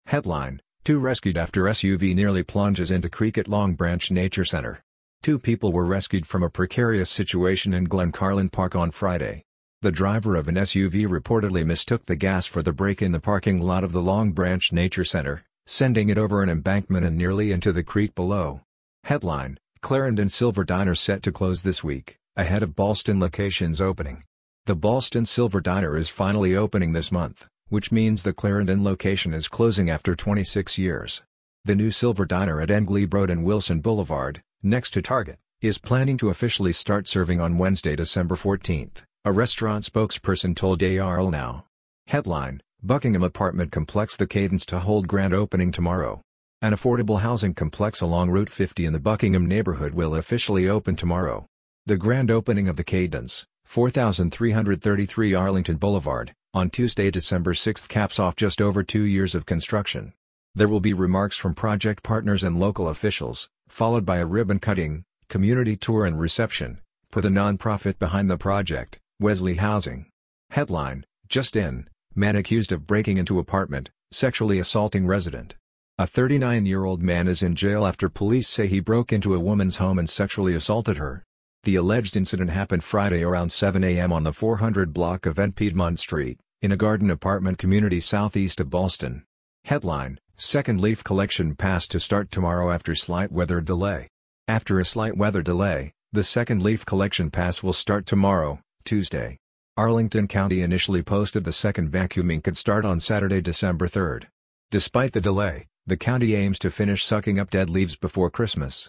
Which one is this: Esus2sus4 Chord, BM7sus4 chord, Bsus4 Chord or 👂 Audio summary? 👂 Audio summary